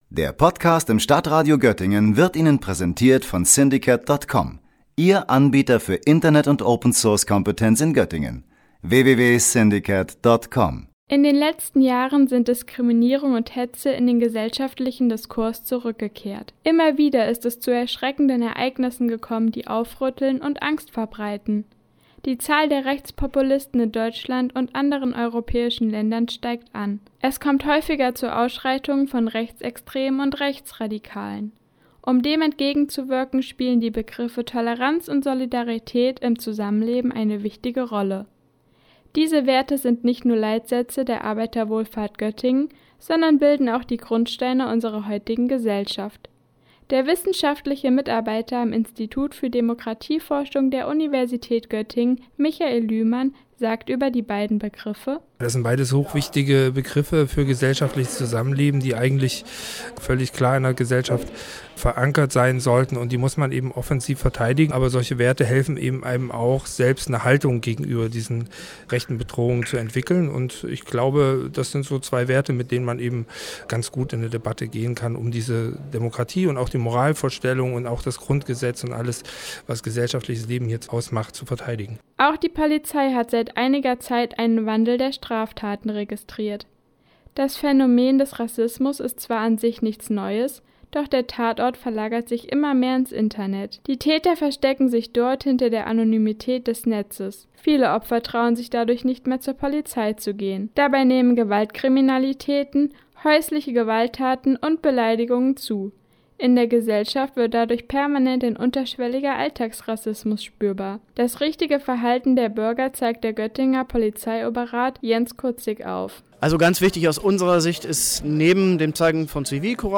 Das vierte AWO-Colloquium unter dem Motto „Toleranz und Solidarität gegen Hetze und Diskriminierung“ widmet sich diesem Thema und informiert Bürger über Handlungsmöglichkeiten vor Ort.